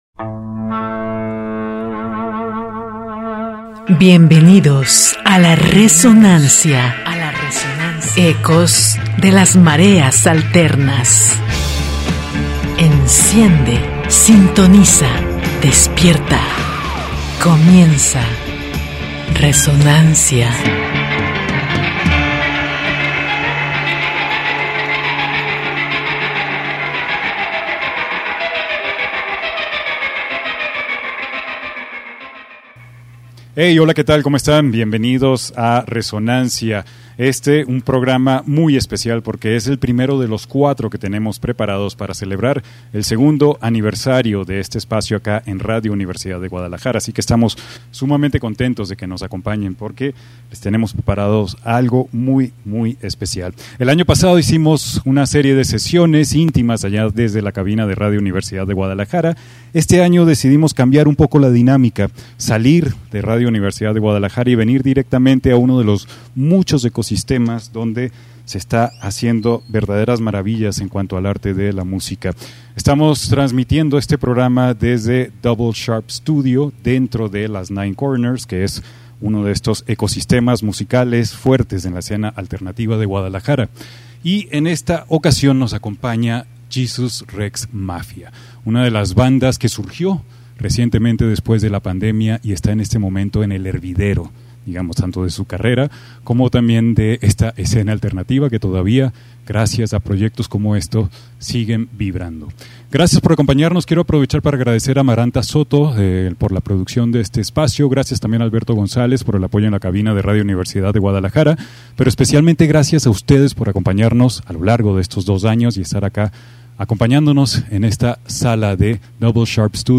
live session
grabada en Double Sharpe Studio